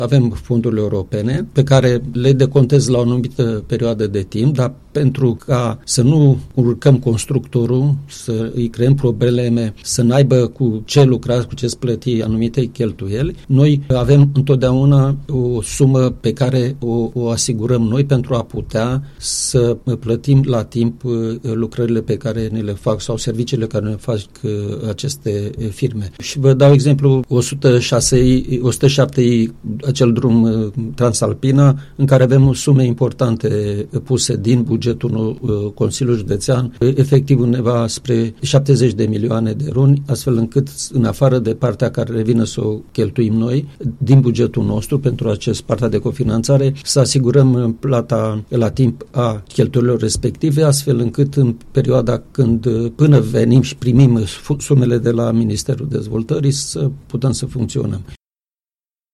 Președintele CJ Alba, Ion Dumitrel, a declarat la Unirea FM că în ceea ce privește proiectele derulate pe fonduri europene, la nivelul instituției există o sumă de bani care poate fi folosită pentru a plăti la timp lucrările derulate de constructori, până când sunt recuperate de la Ministerul Dezvoltării.